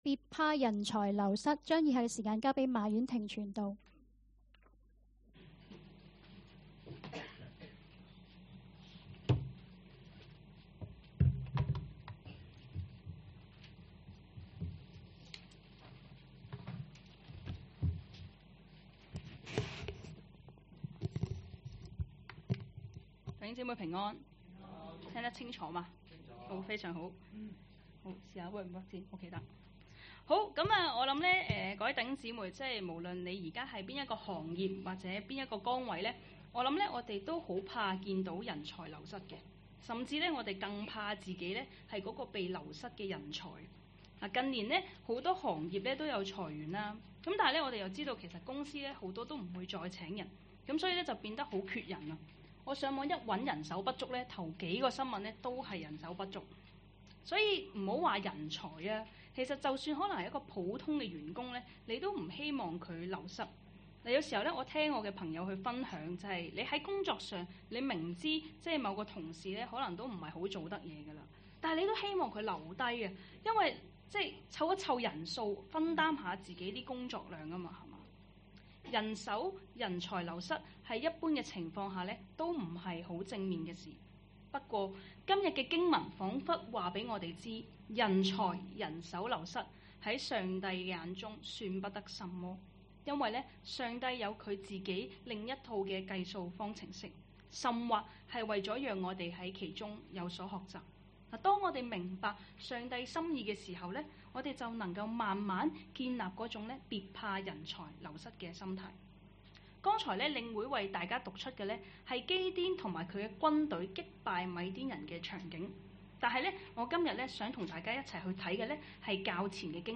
2024年9月28日及29日崇拜
講道 ：別怕人才流失